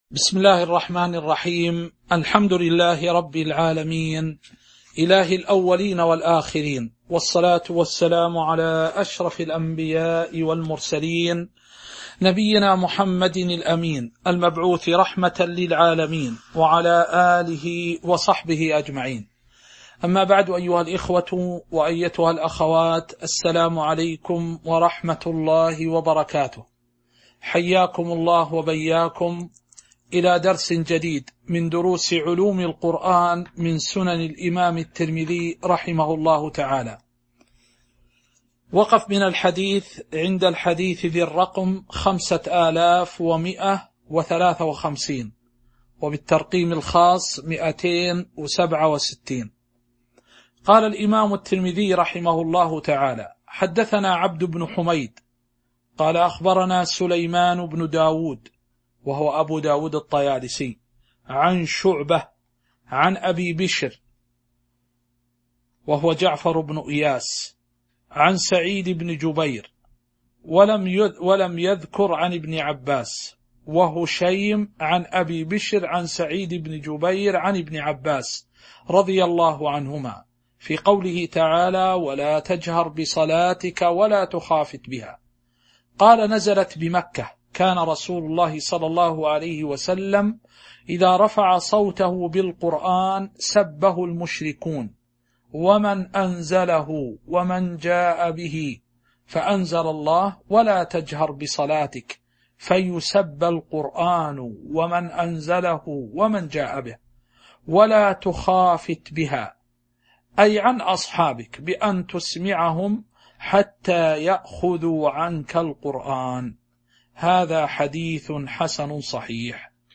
تاريخ النشر ٢٢ جمادى الأولى ١٤٤٣ هـ المكان: المسجد النبوي الشيخ